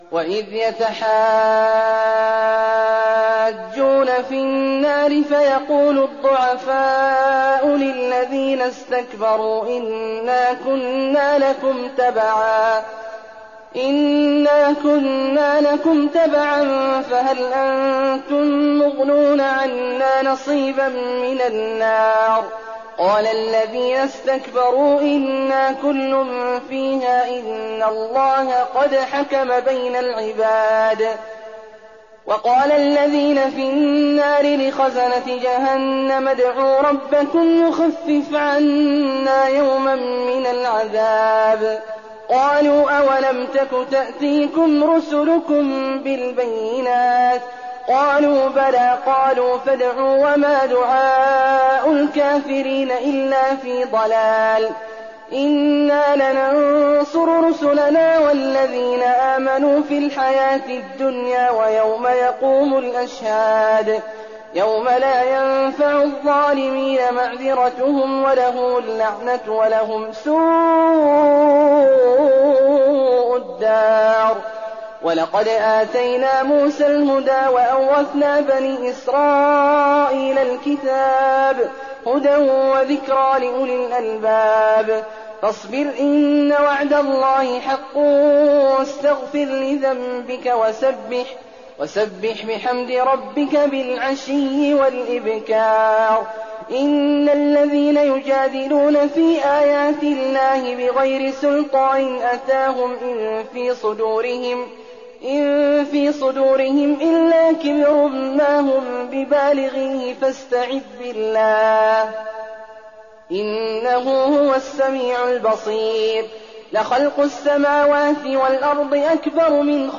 تراويح ليلة 23 رمضان 1419هـ من سور غافر (47-85) وفصلت (1-46) Taraweeh 23rd night Ramadan 1419H from Surah Ghaafir and Fussilat > تراويح الحرم النبوي عام 1419 🕌 > التراويح - تلاوات الحرمين